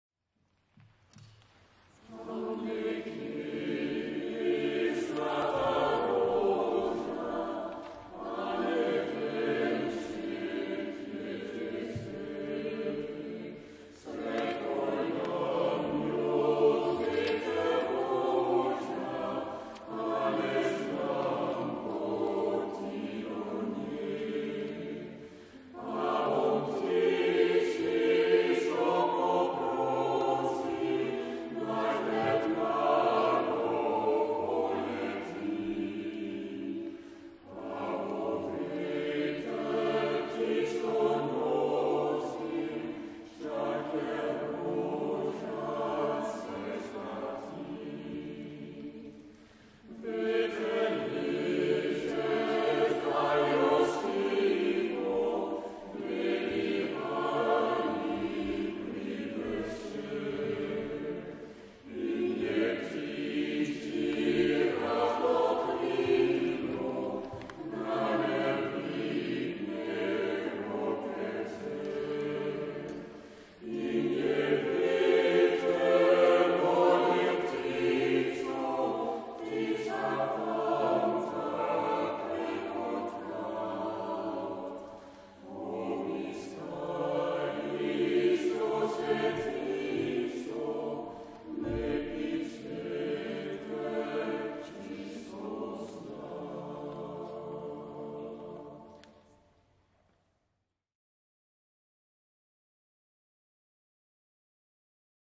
Kamerkoor MusiCanta uit Zeist zingt een veelzijdig repertoire met zowel oude als hedendaagse klassieke muziek uit de 20e en 21e eeuw.